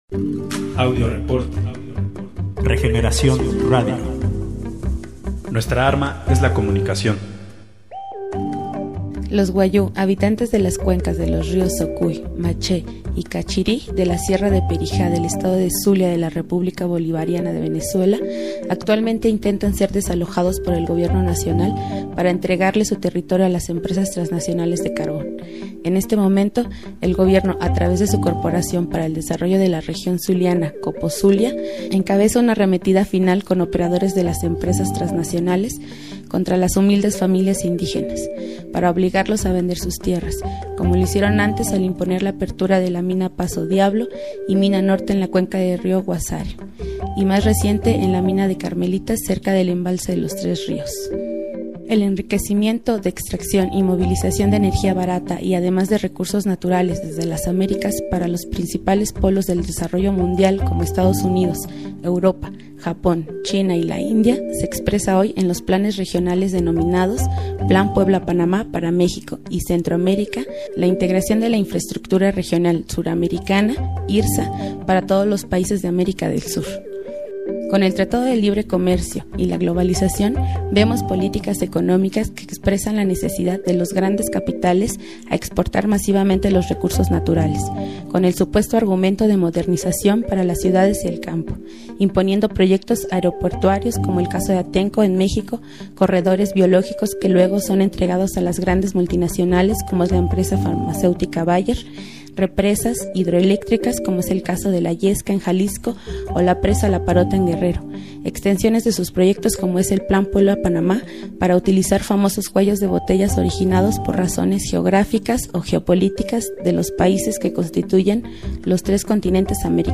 Entrevistas a dos miembros de la organizacion Wayúu